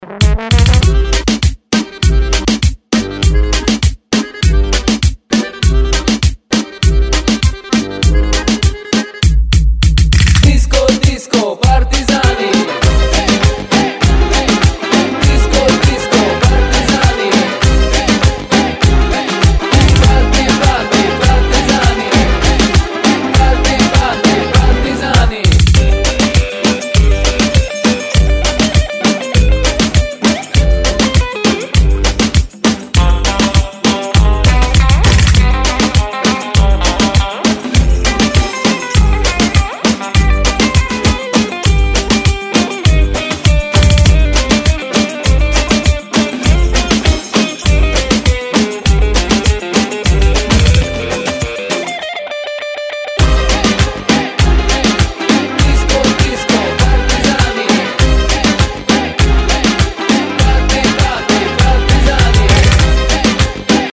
• Качество: 256, Stereo
заводные